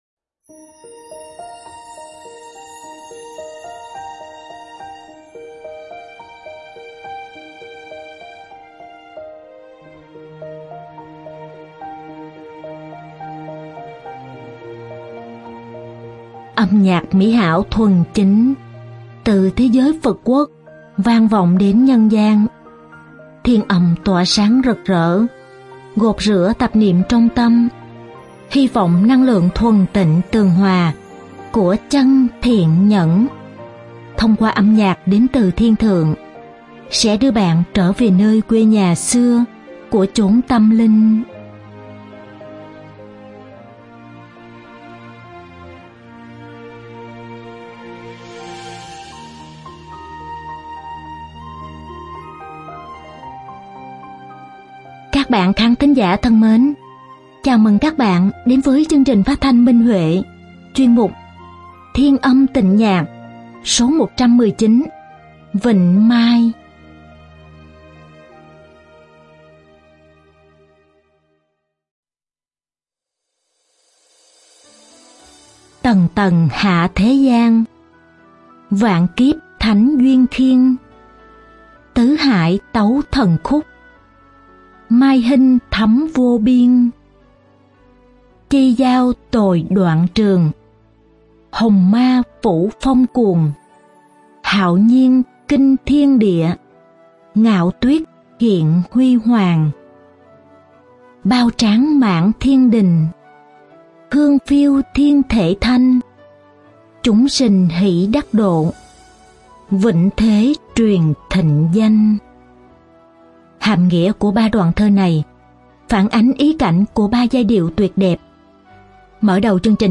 Song tấu đàn nhị hồ và tỳ bà
Ca khúc
Diễn tấu cổ tranh